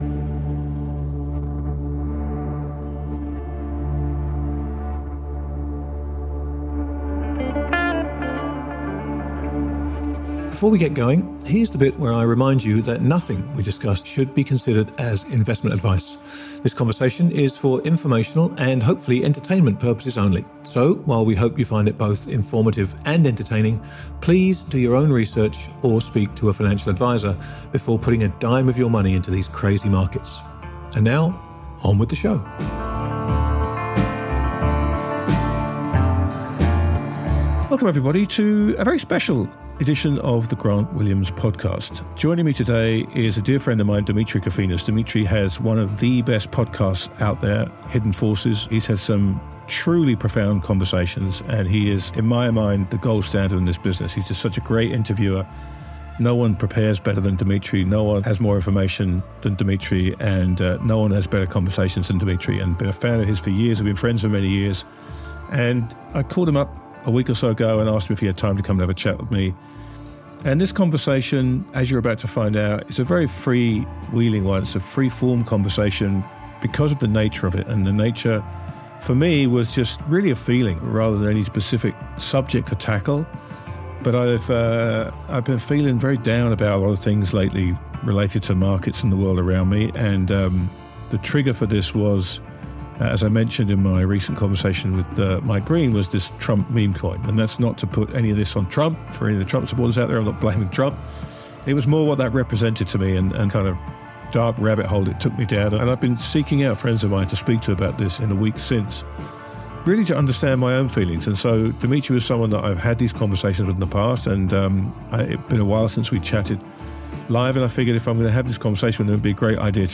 I encourage you to listen to the whole thing, but I wanted to feature a few highlights from the interview (all emphasis is mine).